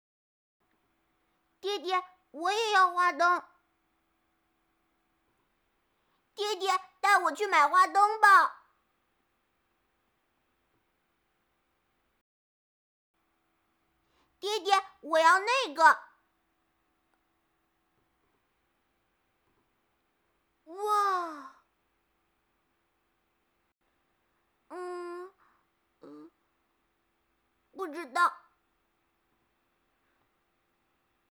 女36 男童.mp3